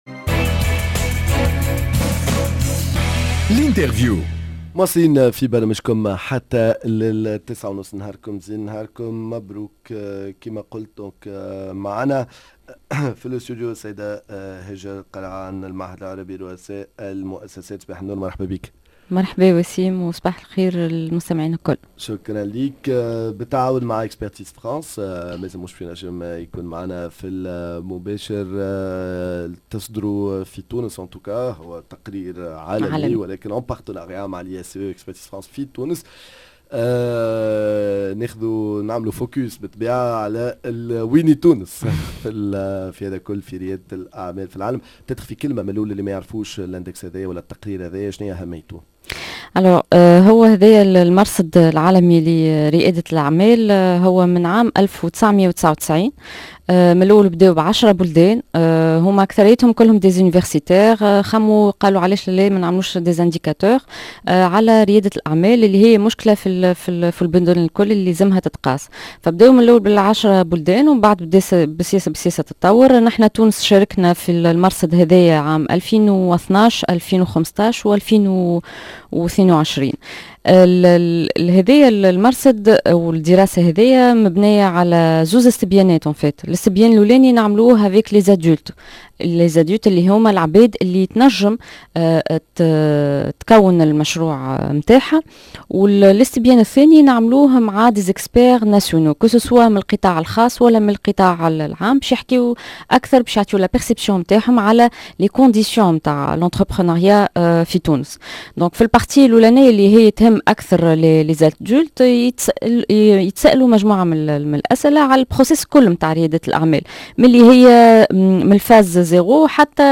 L’interview